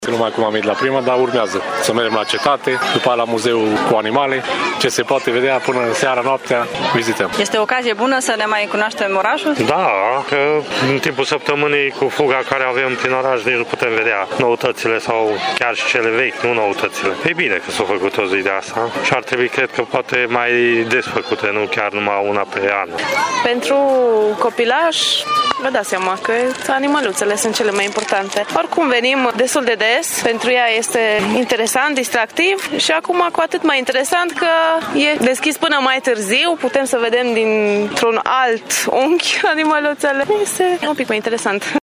Târgumureșenii au venit în număr mare la Grădina Zoologică.